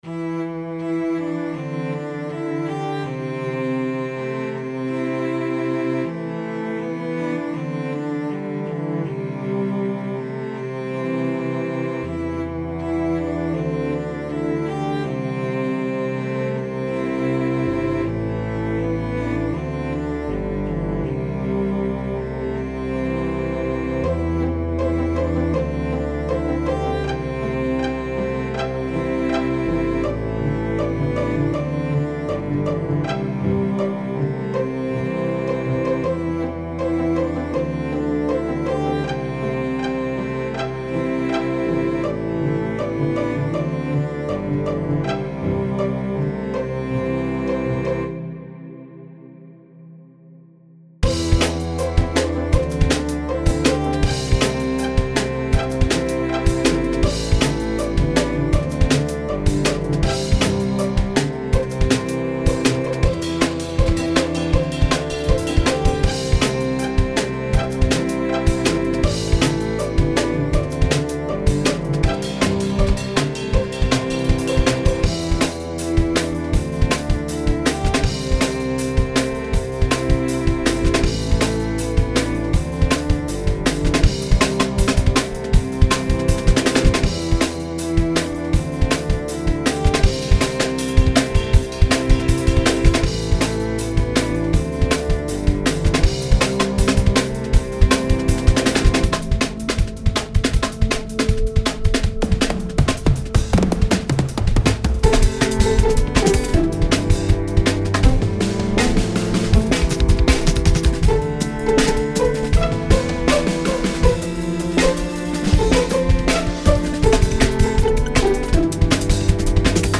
pizzicatos
these brash energetic tracks will not be silenced